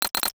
NOTIFICATION_Metal_08_mono.wav